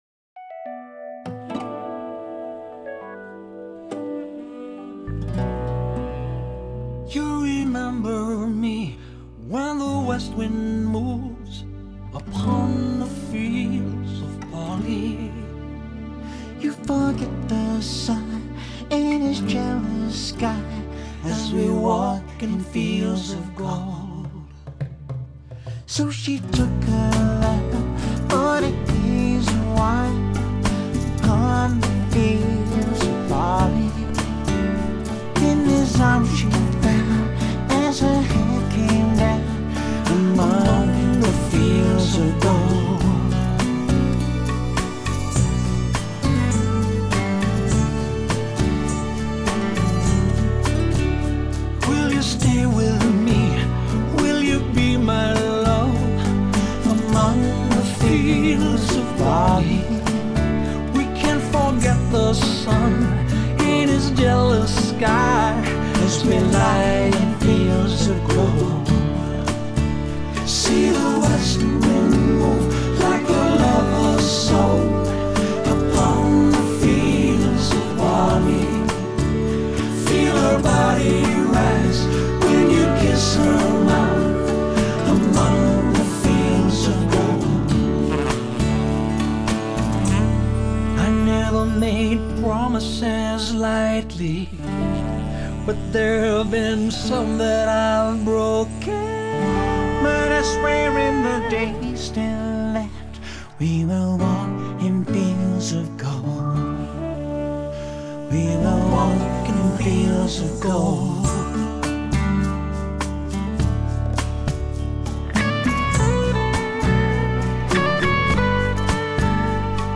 en un álbum de duetos